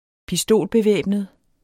Udtale [ piˈsdoˀlbeˌvεˀbnəð ]